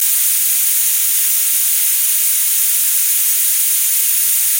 Звуки шиномонтажа